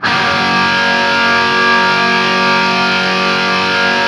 TRIAD F# L-R.wav